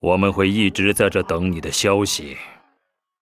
文件 文件历史 文件用途 全域文件用途 Vanjelis_fw_03.ogg （Ogg Vorbis声音文件，长度3.2秒，107 kbps，文件大小：42 KB） 源地址:地下城与勇士游戏语音 文件历史 点击某个日期/时间查看对应时刻的文件。 日期/时间 缩略图 大小 用户 备注 当前 2018年5月13日 (日) 02:56 3.2秒 （42 KB） 地下城与勇士  （ 留言 | 贡献 ） 分类:范哲利斯 分类:地下城与勇士 源地址:地下城与勇士游戏语音 您不可以覆盖此文件。